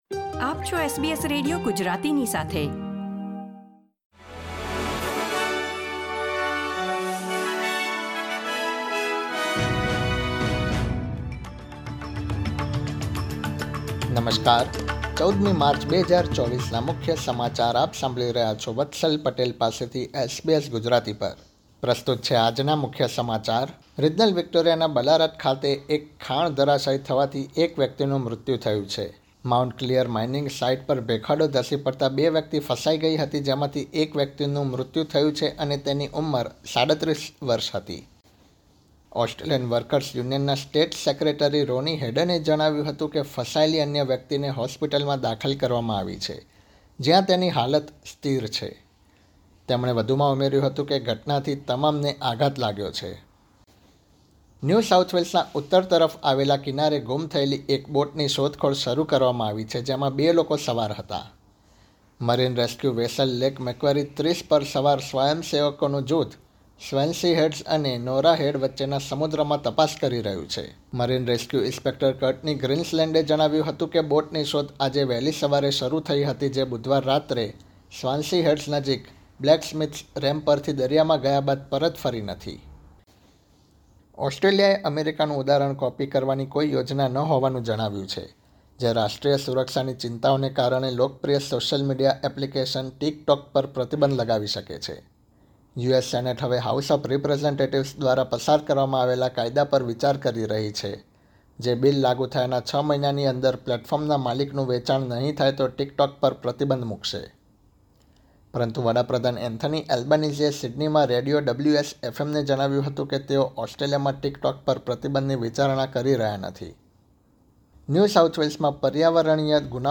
SBS Gujarati News Bulletin 14 March 2024